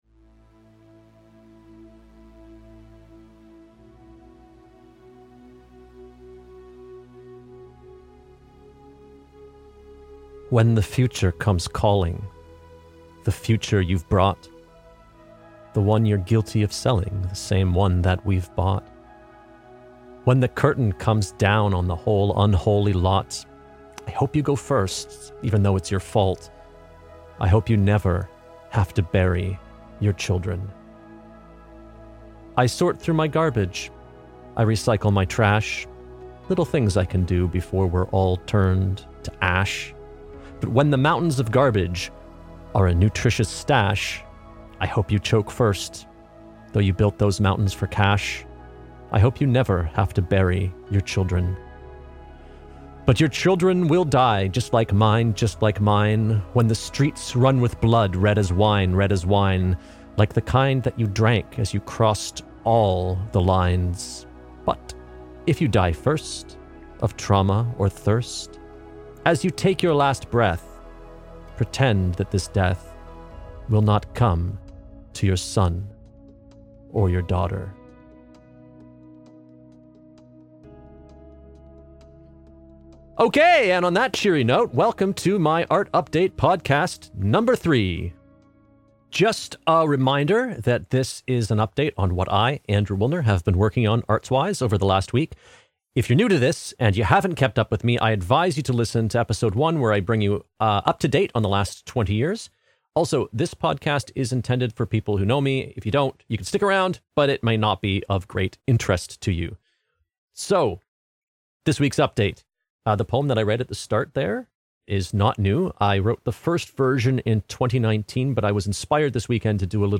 Be ready, I lead off with a pretentious poetry reading! Updates in this episode: I will be trying to record and release one episode a week, and I can't commit to a specific day, so will be releasing on Monday, Tuesday, or Wednesday each week.